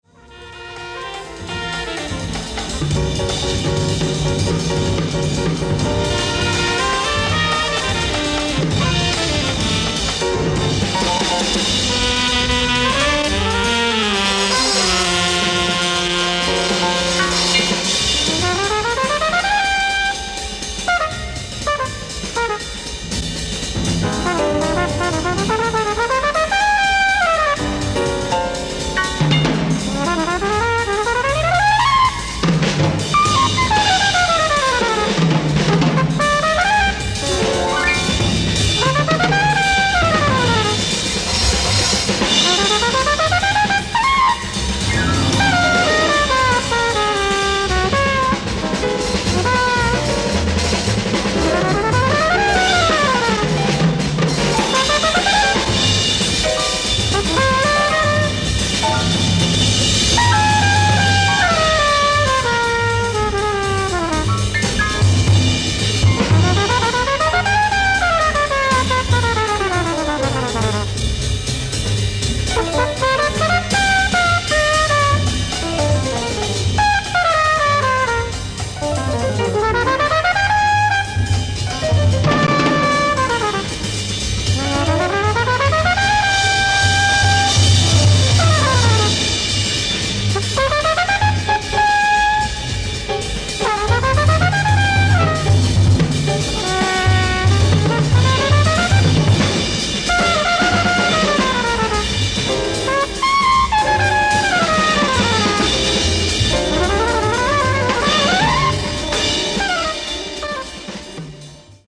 ライブ・アット・フィルハーモニー、ベルリン、ドイツ
※試聴用に実際より音質を落としています。